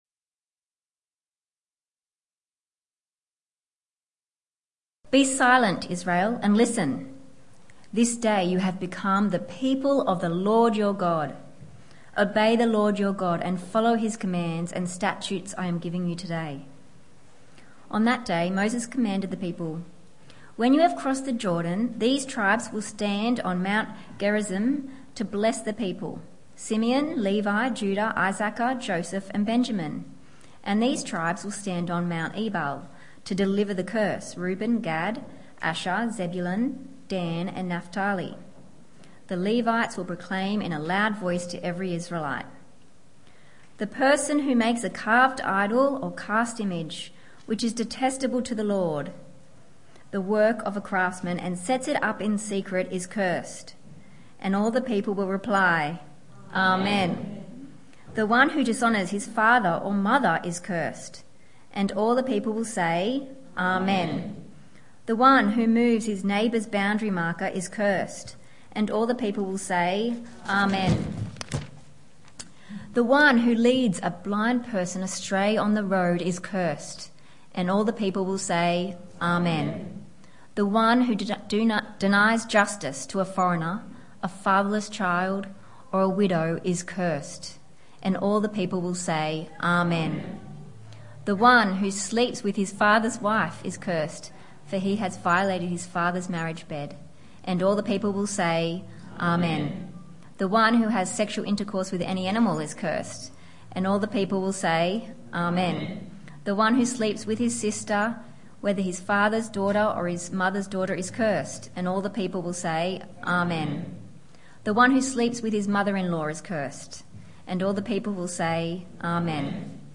Saturday Church